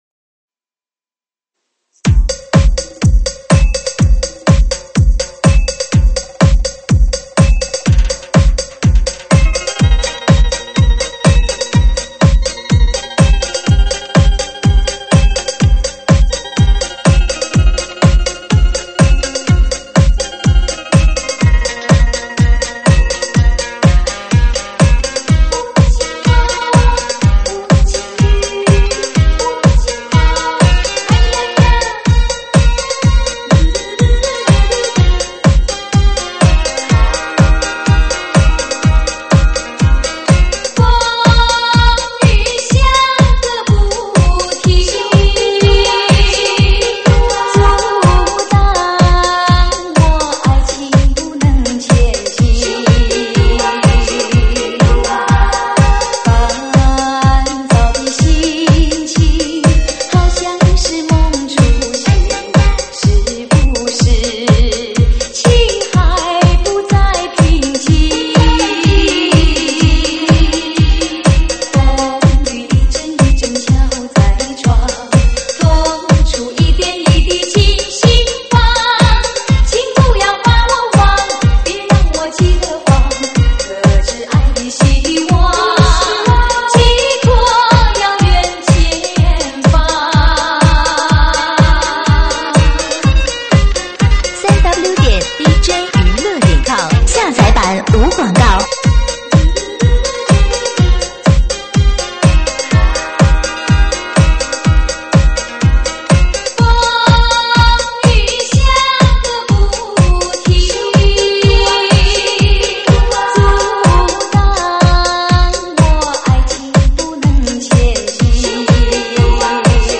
舞曲类别：快四